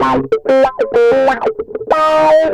Track 13 - Distorted Guitar Wah 03.wav